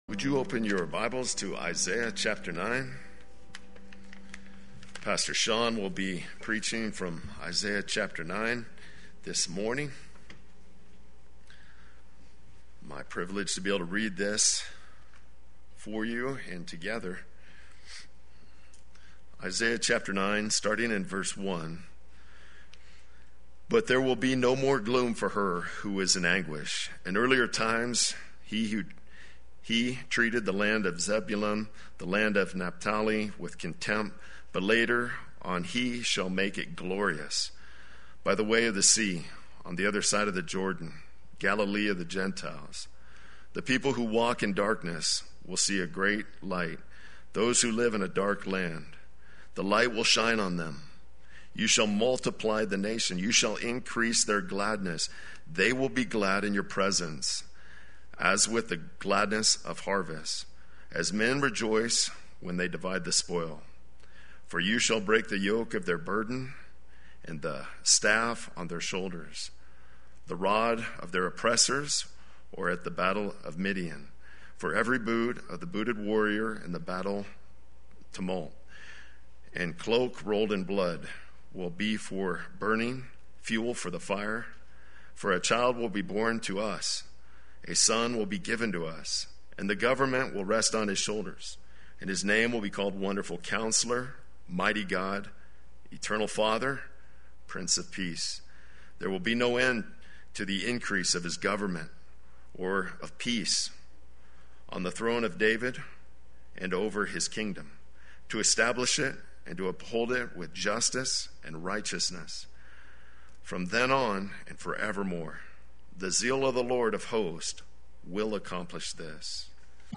Play Sermon Get HCF Teaching Automatically.
“The Prince of Peace” Sunday Worship